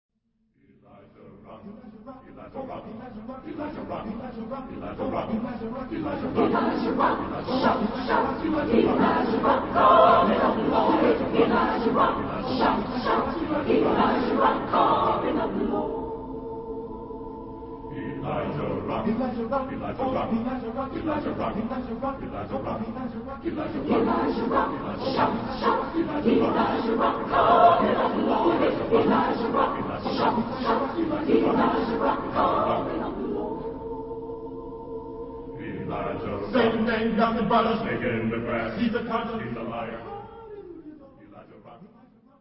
Genre-Stil-Form: Spiritual ; geistlich
Chorgattung: SATB divisi  (4 gemischter Chor Stimmen )
Tonart(en): a-moll